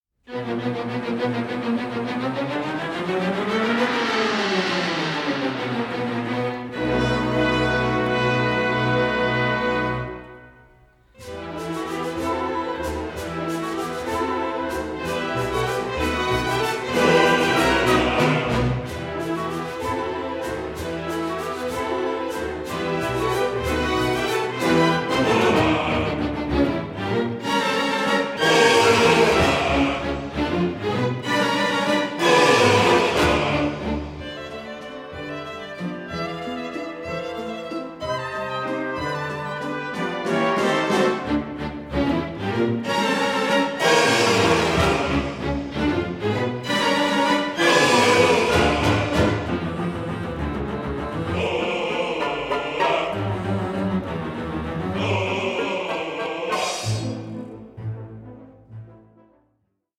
Opera in Three Acts
a buoyant blend of ragtime, vaudeville and grand opera